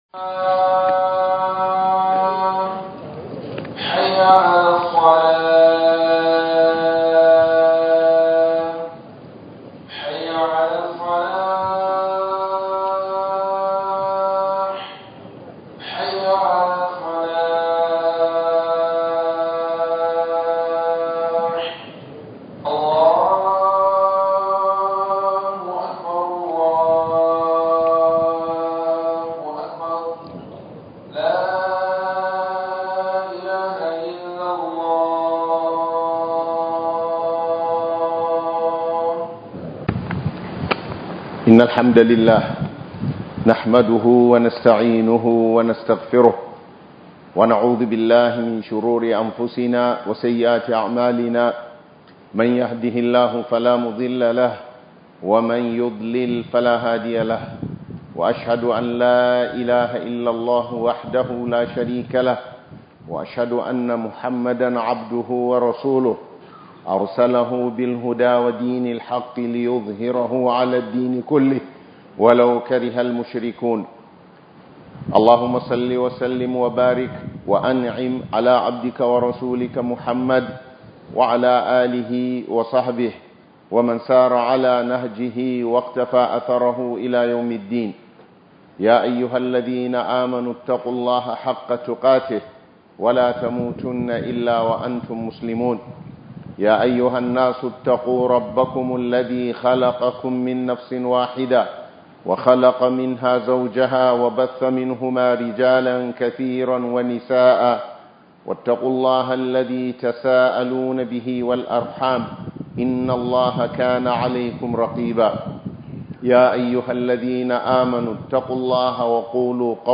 Annasihat - Yin Nasiha - HUDUBA